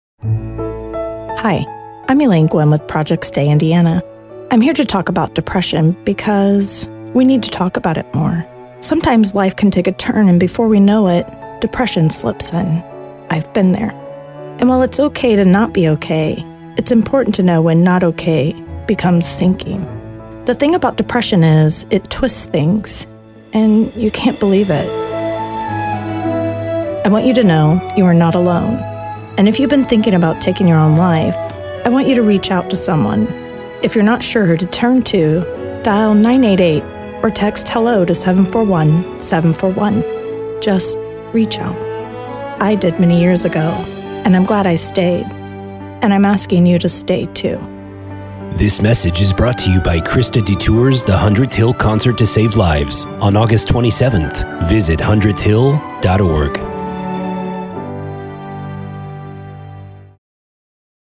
Radio Public Service Annoucements